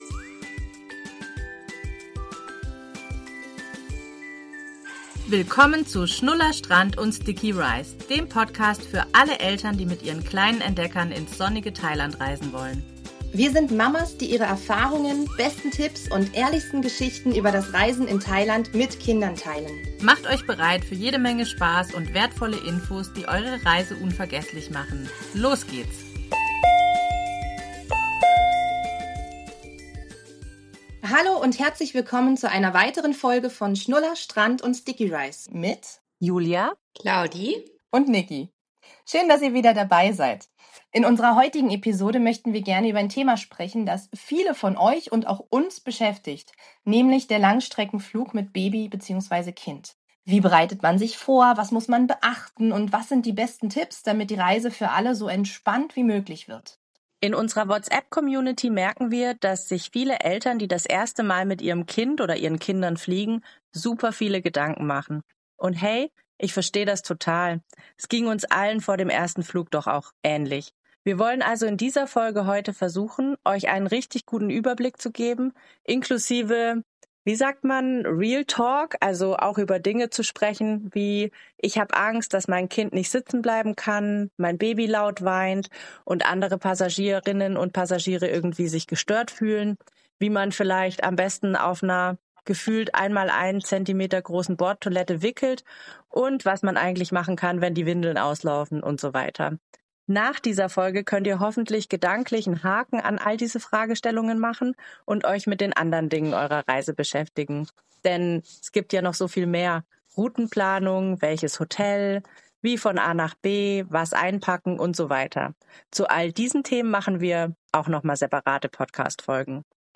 drei Mamas mit Fernweh, Sonnencreme im Gepäck und ganz viel Herz für Thailand.